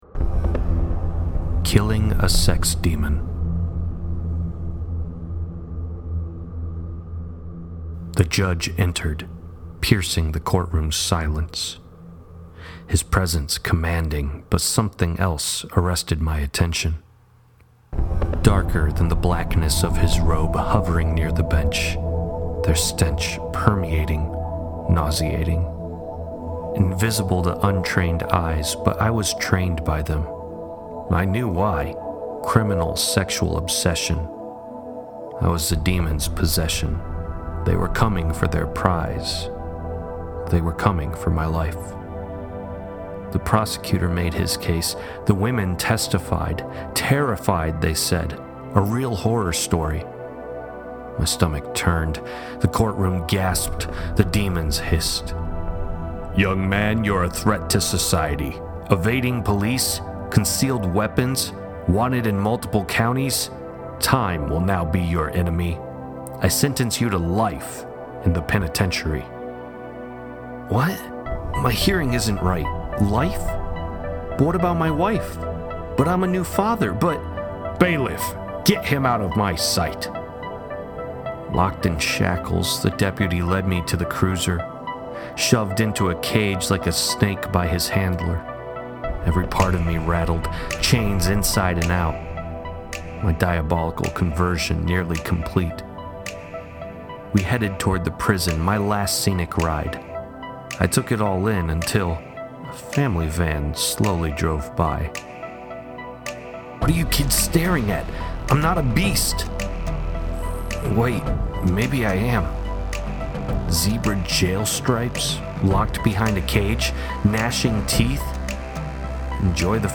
jesus-the-ex-con-poem.mp3